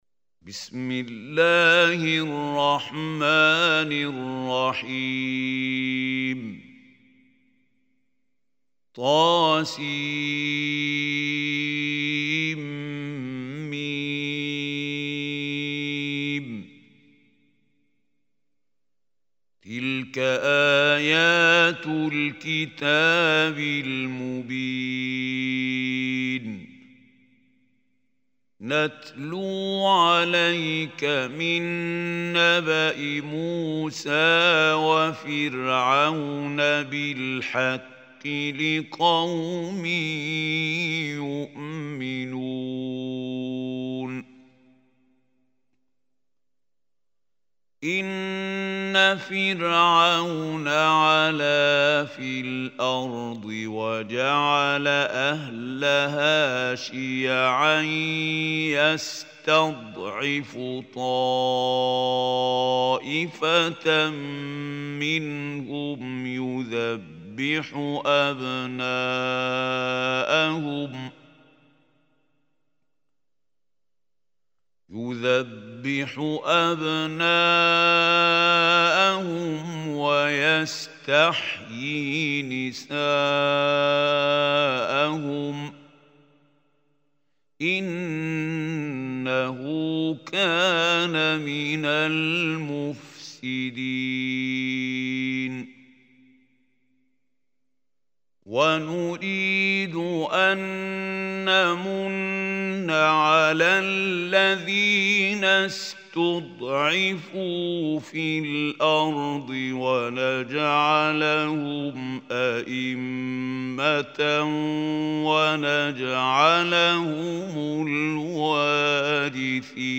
Listen online and download beautiful Quran tilawat/ Recitation of Surah Al Qasas in the voice of Mahmoud Khalil Al Hussary.